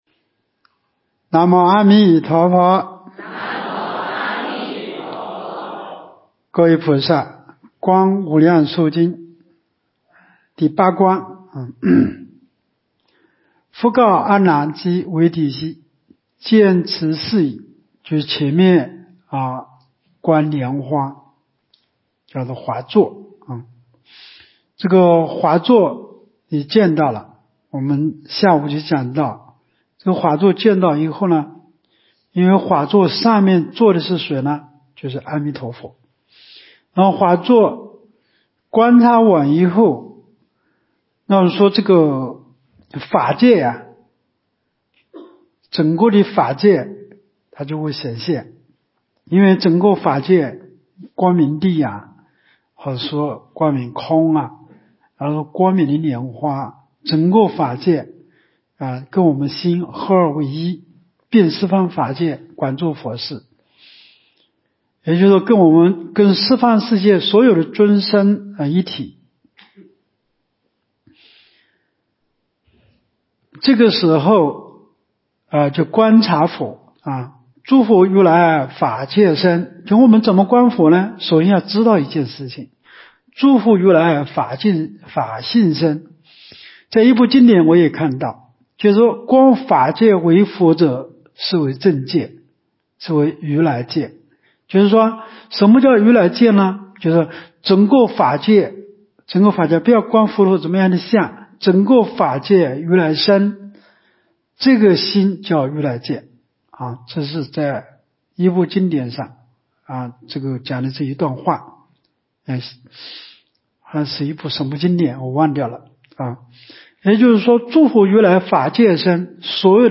无量寿寺冬季极乐法会精进佛七开示（26）（观无量寿佛经）...